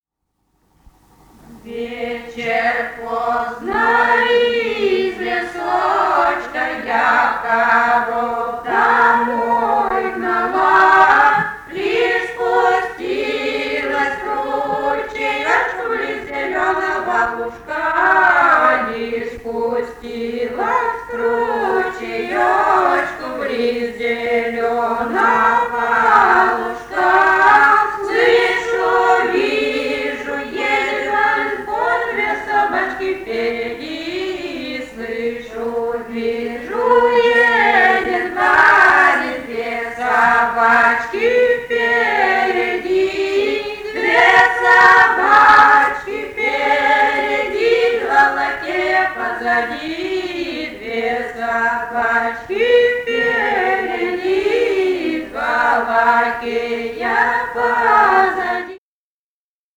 полевые материалы
«Вечер поздно из лесочка» (лирическая).
Костромская область, с. Островское Островского района, 1964 г. И0788-03